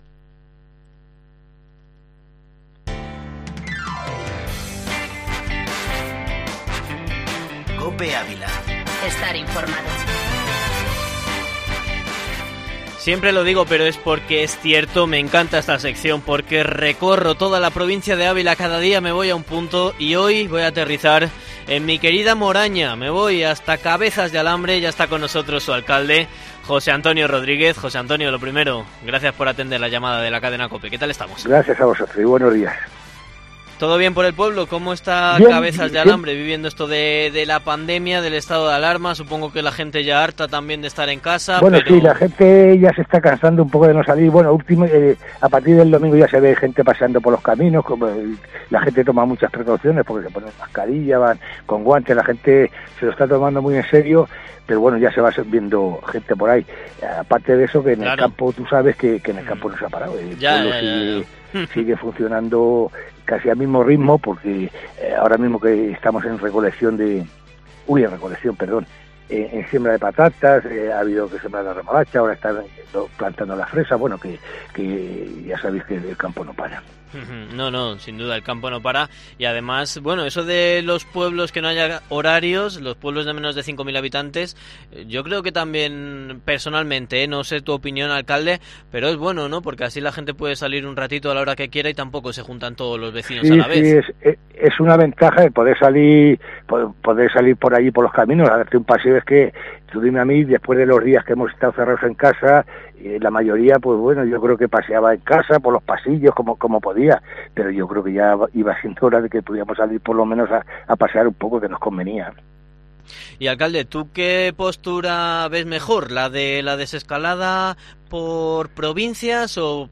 ENTREVISTA
El alcalde de Cabezas de Alambre, José Antonio Rodríguez en COPE